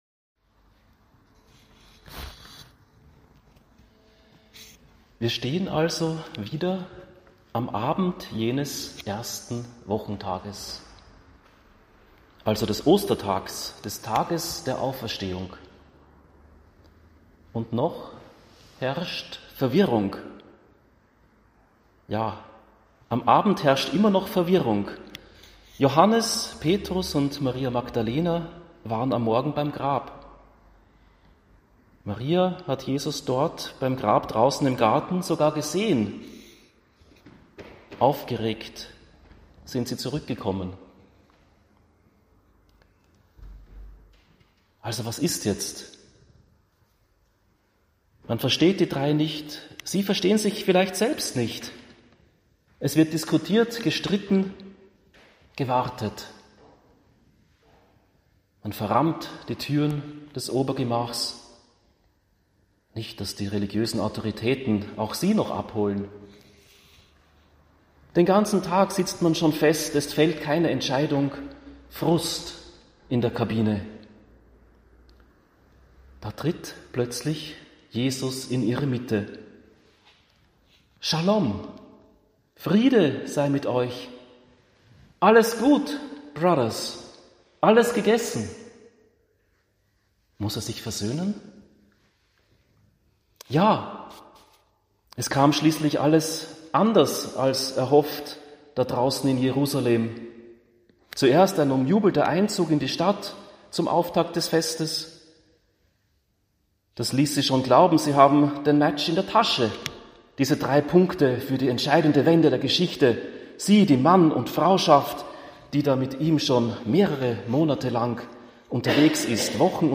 Predigten aus der Mirjam-Kirche
Vergebung auf den Platz bringen. Die Fussball-Pfingstpredigt zum Hören.mp3